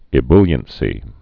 (ĭ-blyən-sē, ĭ-bŭl-)